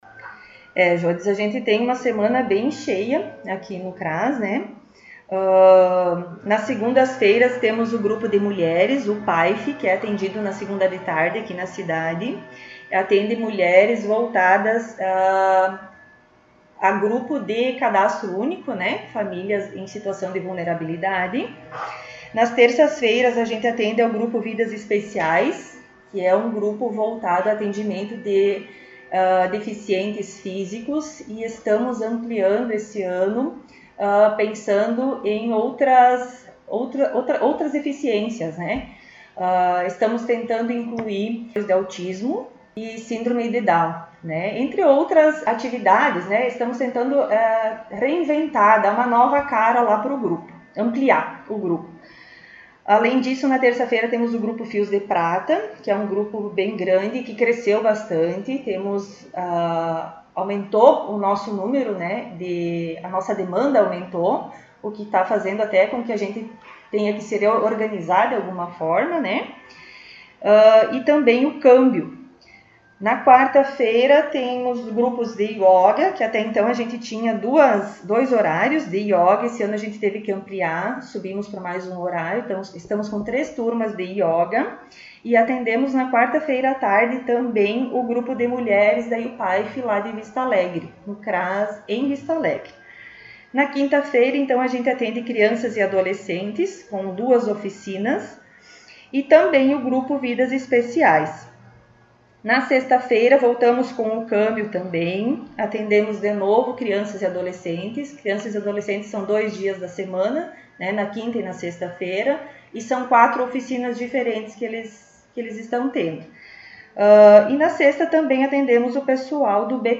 Diretora do CRAS concedeu entrevista